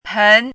“盆”读音
pén
盆字注音：ㄆㄣˊ
国际音标：pʰən˧˥
pén.mp3